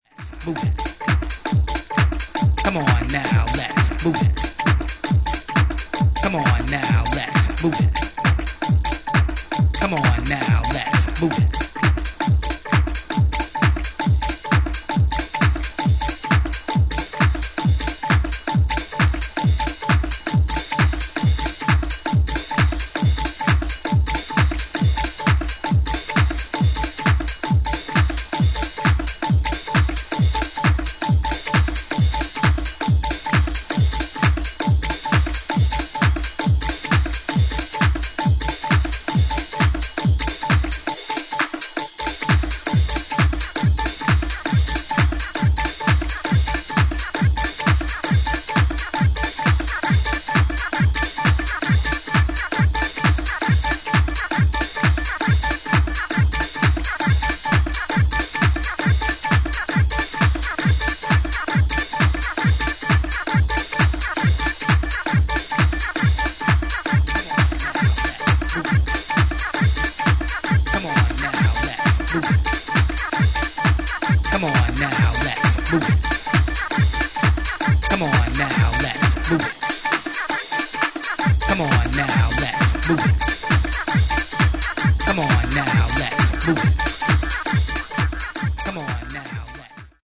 そこに加わるバウンスしたグルーヴは、長い年月を経ても尚踊らせる事への訴求力に満ちています。